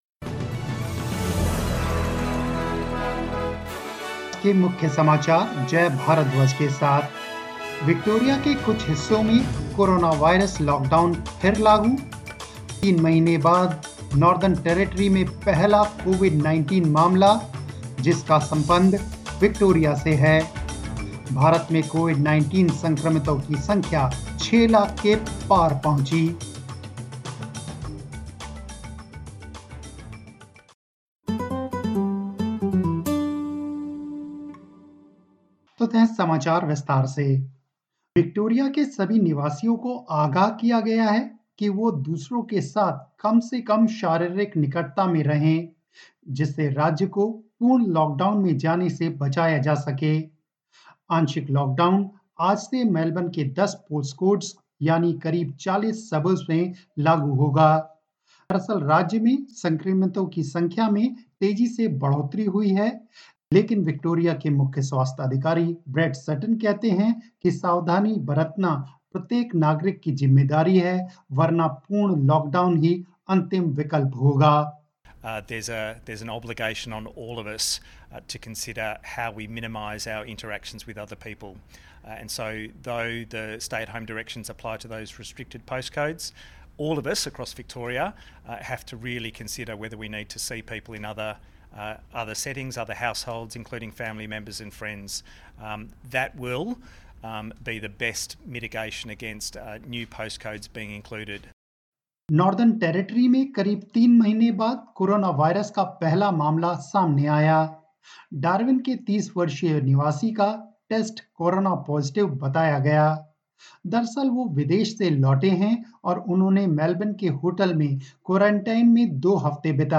News in Hindi 02 July 2020